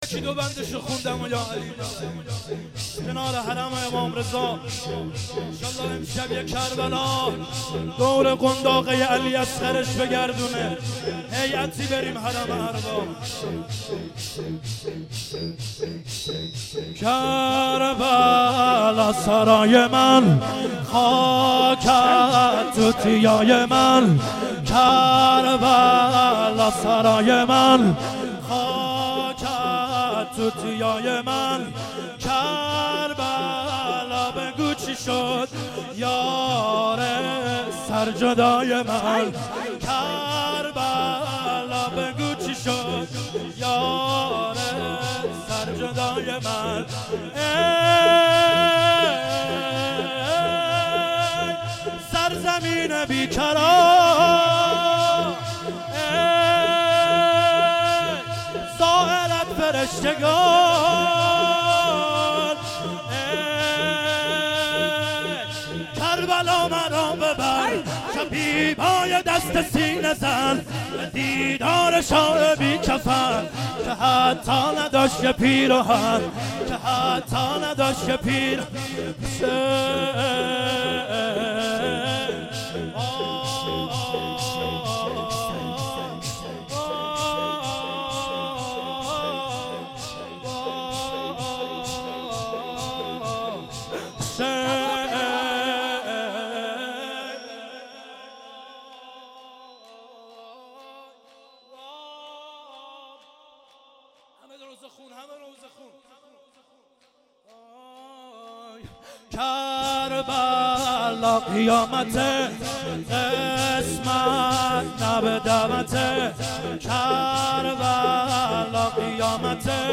محرم1393
شور